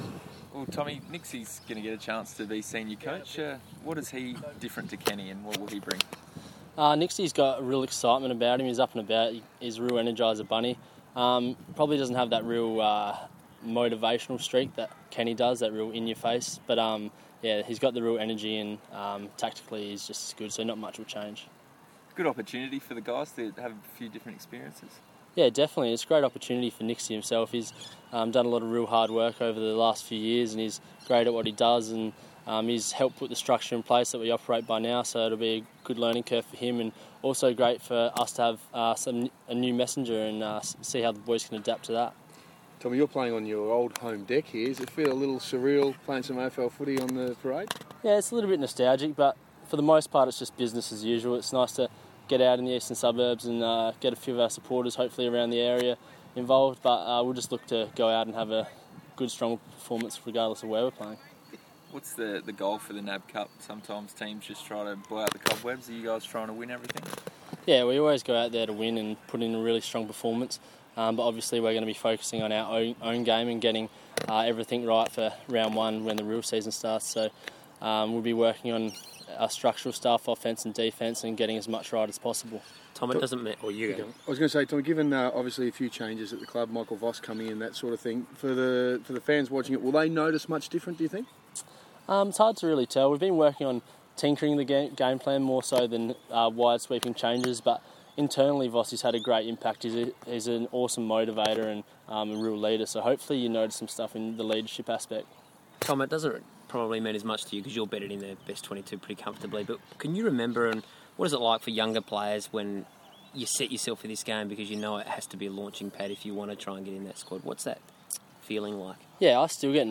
Tom Jonas press conference - Thursday March 5 2015
Tom Jonas is interviewed at Norwood Oval ahead of the club's first NAB Challenge game for 2015